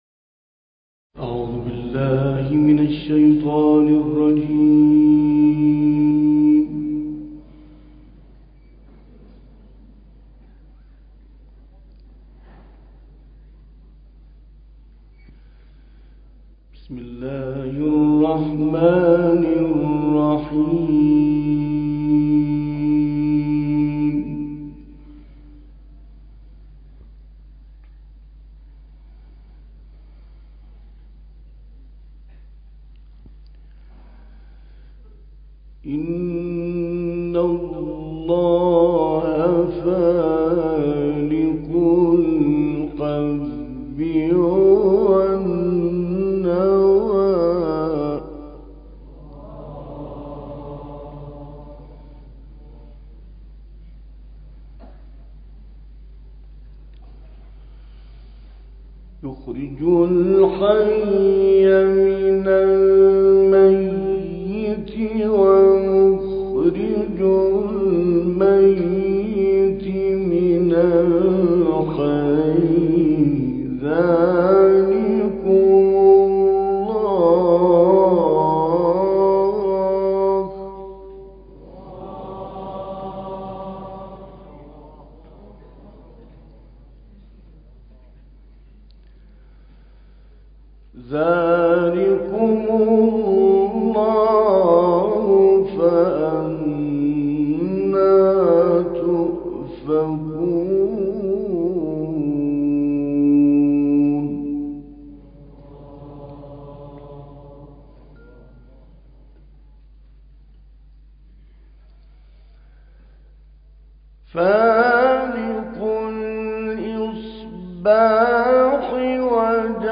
استاد محمد عبدالوهاب الطنطاوی قاری برجسته مصری این تلاوت زیبا را که آیات 95 تا 104 سوره انعام را شامل می شود در دانشگاه امام حسین (ع) در جمع سرداران سپاه پاسداران اجرا کرد.